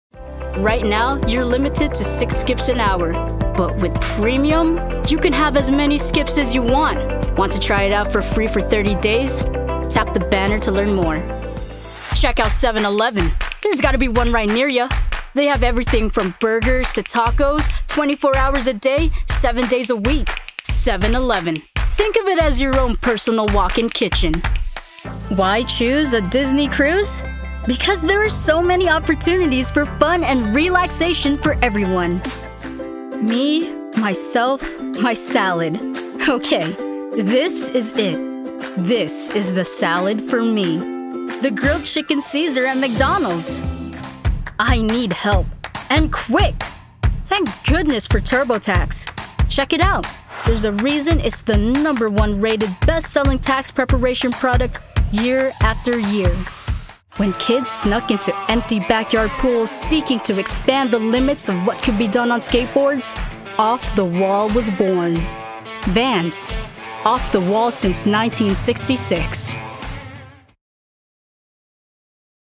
Teen
Young Adult
Commercial